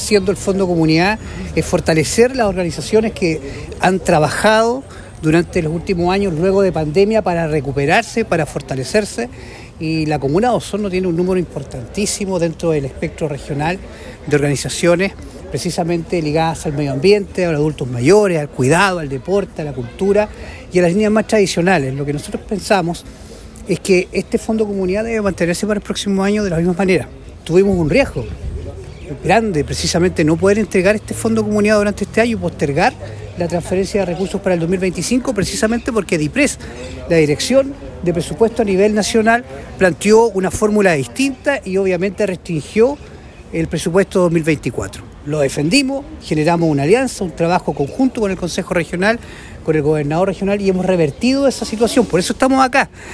Ayer jueves, en la Casa del Folclor de Osorno, se llevó a cabo la Ceremonia de Firma de Convenio del Fondo Comunidad, donde 193 organizaciones territoriales y funcionales de la comuna recibieron recursos destinados a la ejecución de proyectos en diversas áreas, como Seguridad Ciudadana, Adultos Mayores, Fondo Social, entre otras.
Por su parte, el Consejero Regional Francisco Reyes subrayó la importancia de estos fondos para el desarrollo de las organizaciones y mencionó las dificultades enfrentadas este año debido a disposiciones de la Dirección de Presupuesto.